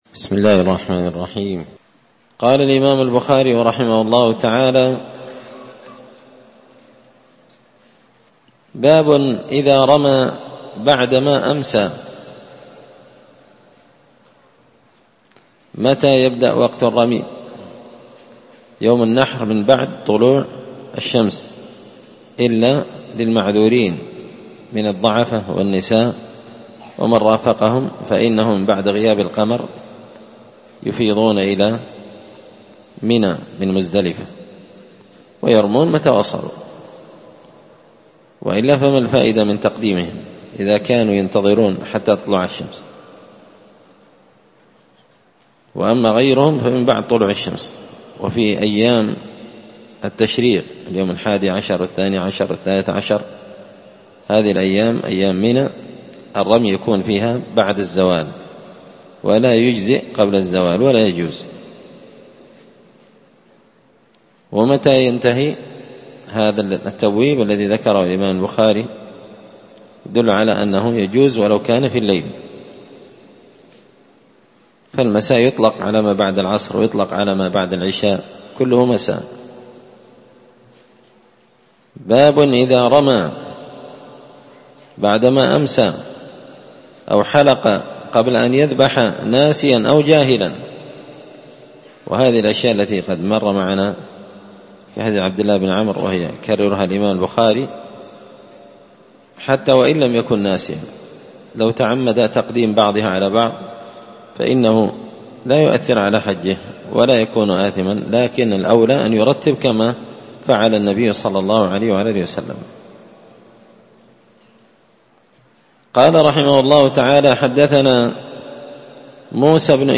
مسجد الفرقان قشن المهرة اليمن